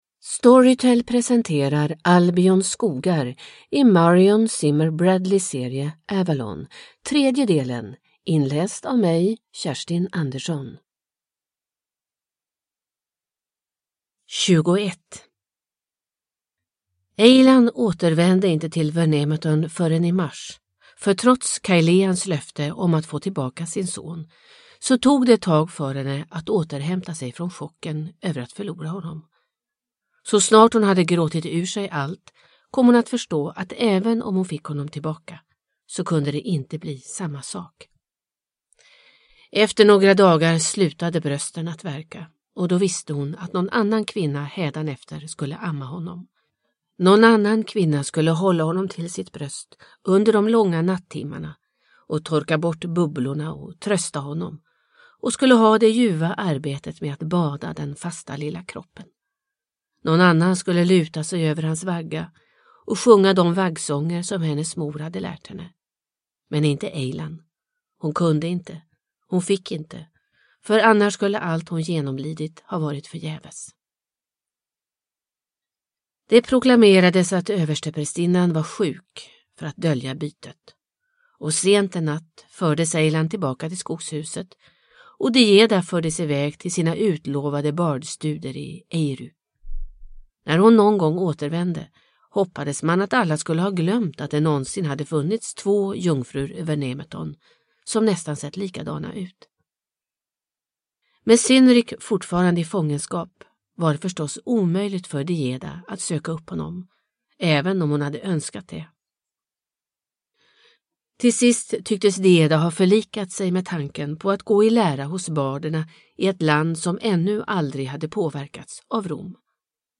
Albions skogar – del 3 – Ljudbok – Laddas ner